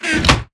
chestopen.ogg